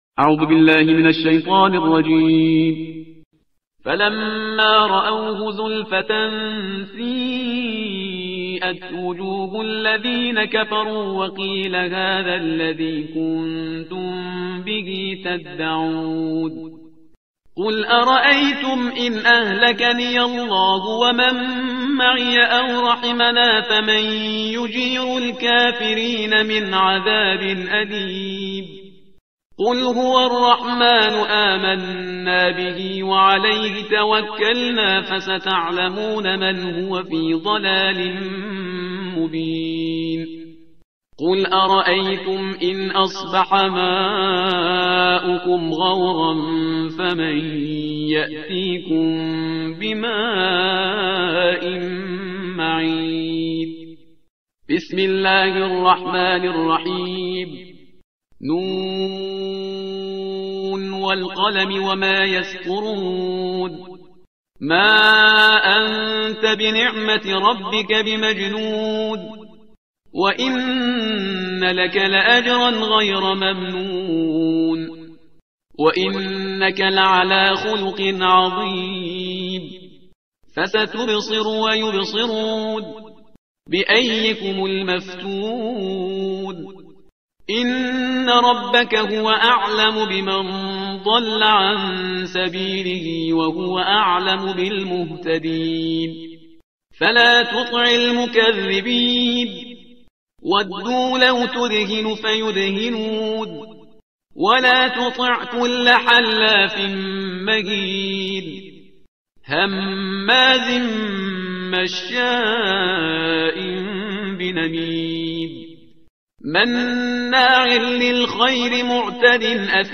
ترتیل صفحه 564 قرآن با صدای شهریار پرهیزگار